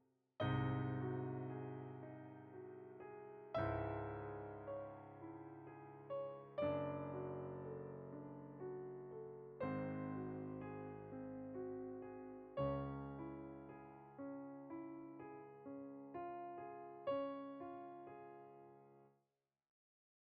La tonalidad de esta pieza es Do# menor, en este caso, el acorde napolitano sería un Re Mayor.
La progresión compuesta por Beethoven viene del acorde dominante (Sol sostenido), para ir a la tónica (Do sostenido menor) y luego el acorde napolitano (Re mayor).